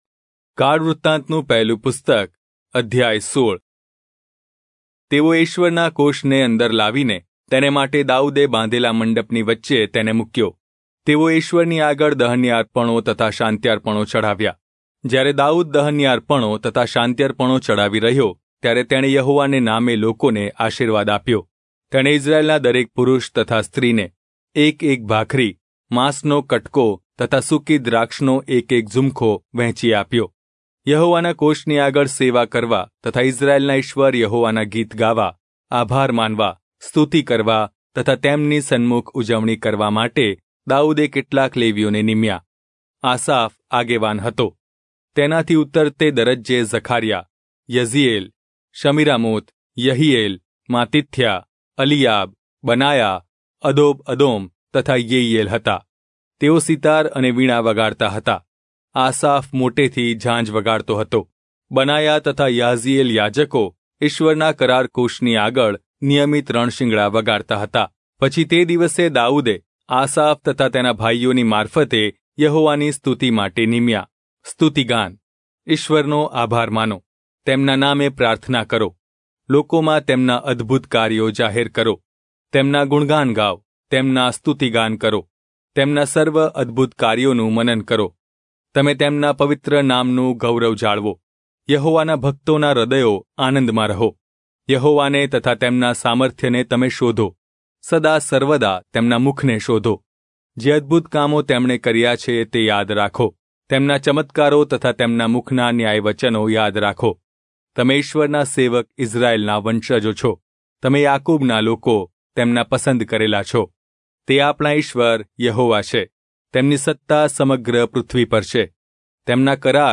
Gujarati Audio Bible - 1-Chronicles 2 in Irvgu bible version